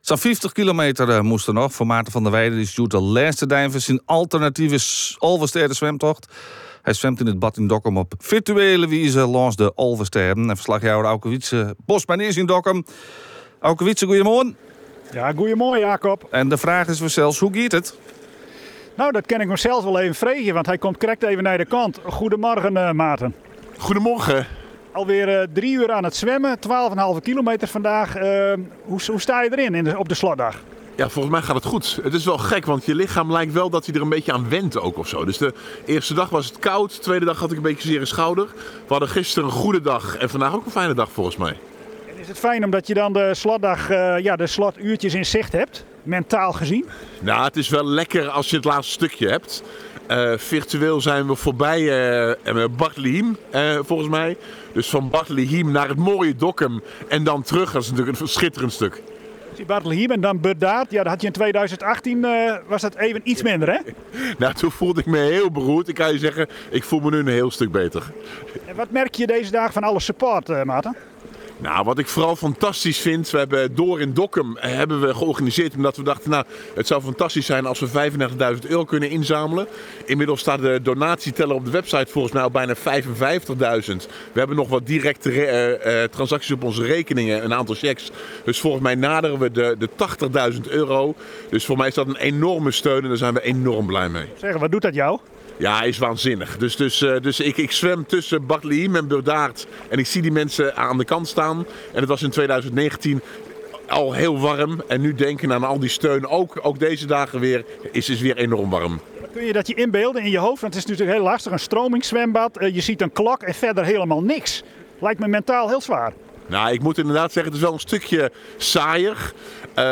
verslaggever
in gesprek met Maarten van der Weijden